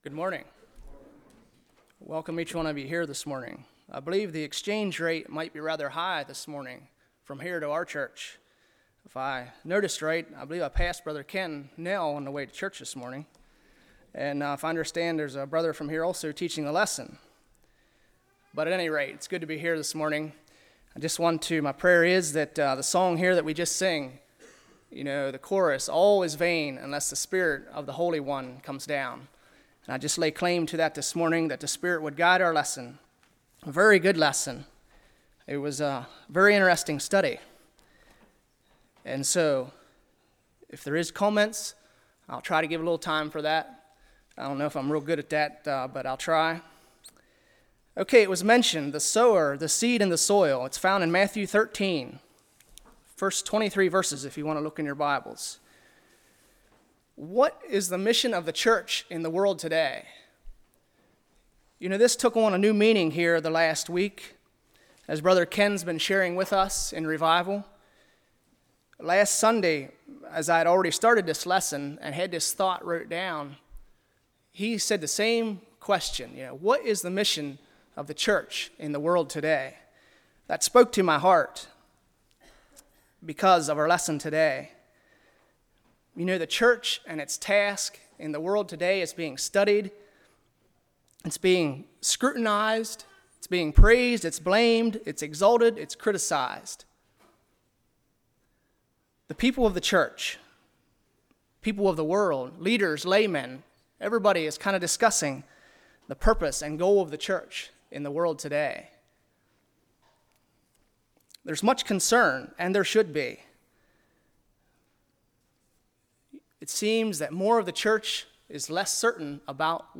Spring Revival 2012 Passage: Matthew 13:1-23 Service Type: Sunday School « The Impact of a Choice Sermon on the Mount